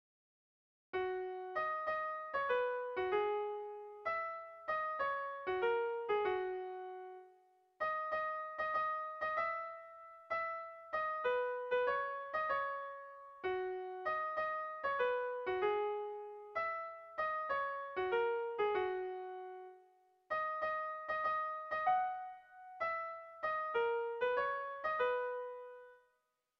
Sentimenduzkoa
Zortziko txikia (hg) / Lau puntuko txikia (ip)
ABAD